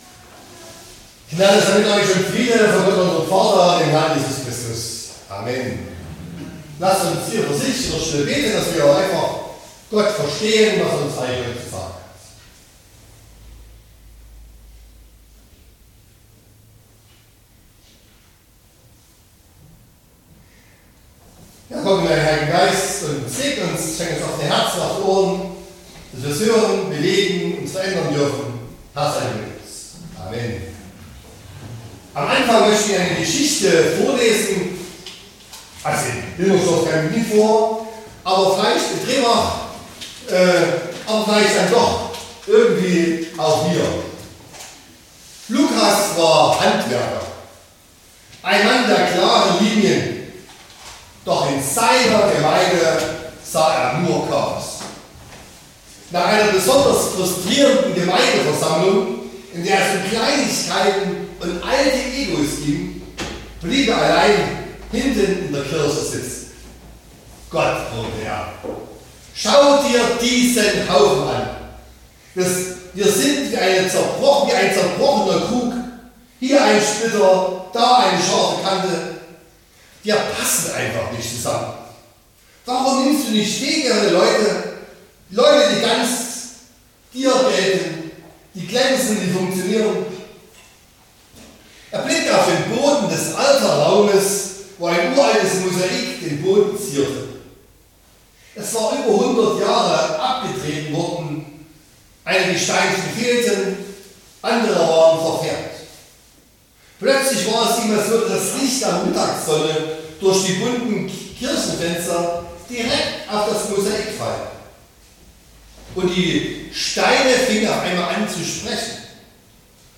25.01.2026 – Gottesdienst
Predigt (Audio): 2026-01-25_Gemeinschaft___Beziehungen__Predigtreihe_2026__Thema_2_.mp3 (26,8 MB)